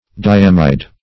Diamide \Di*am"ide\ (?; 104), n. [Pref. di- + amide.] (Chem.)